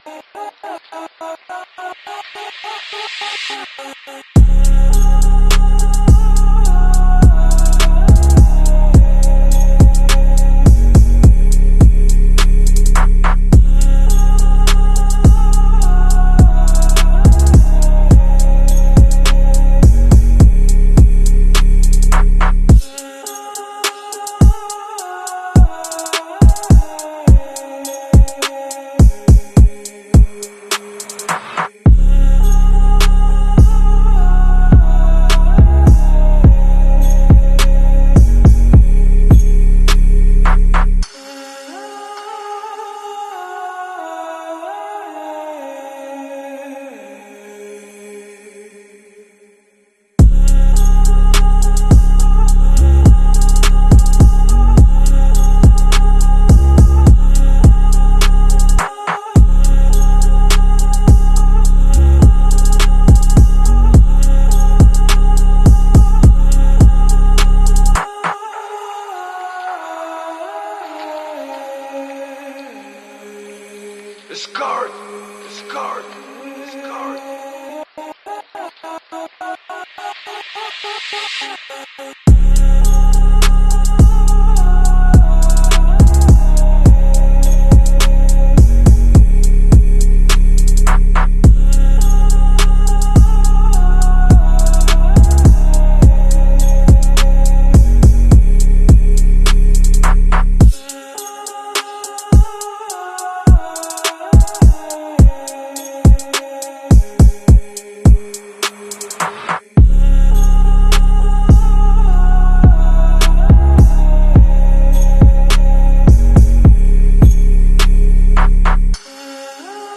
Calling Feral Hogs With The Sound Effects Free Download